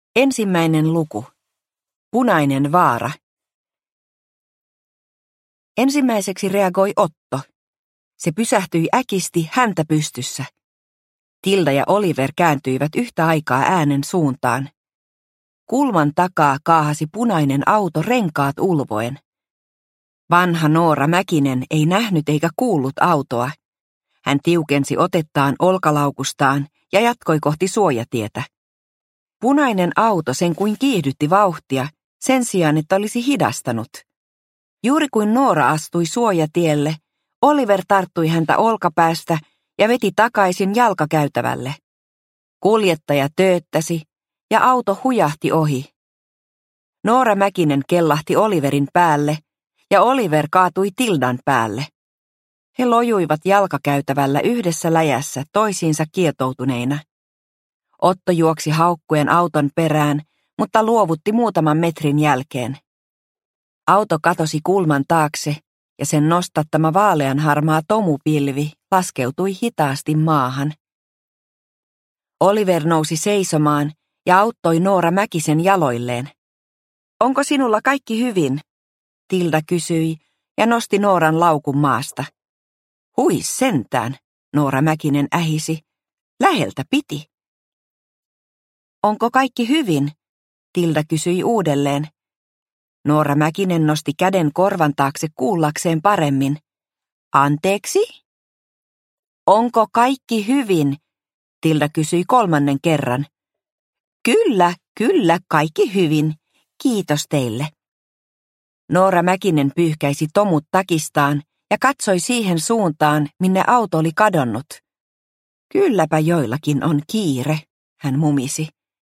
Operaatio Auringonlasku – Ljudbok – Laddas ner